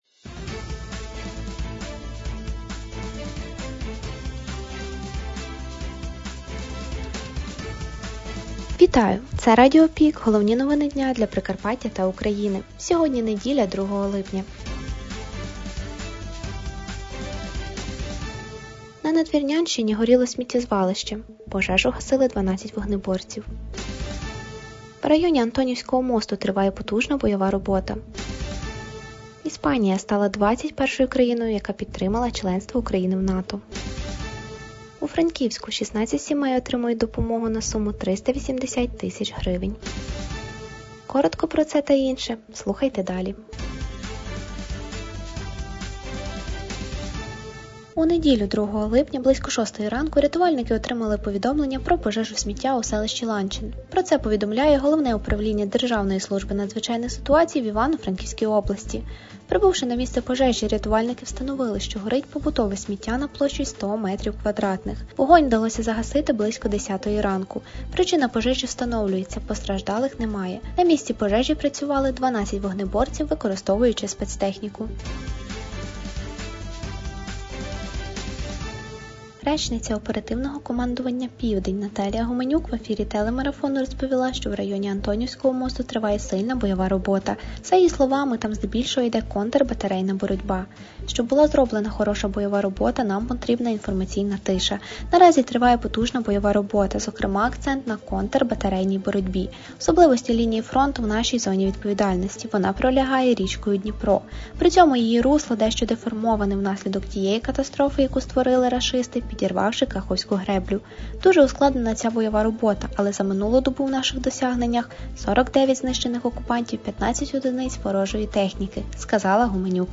Пропонуємо вам актуальне за день - у радіоформаті.